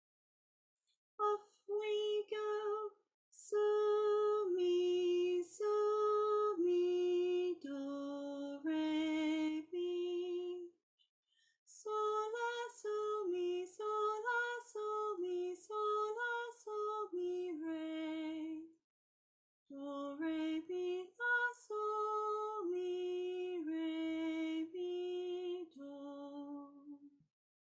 We know we’re in do pentatonic but not every bar contains every note.
When you’re happy with your answers, sing the song in solfa.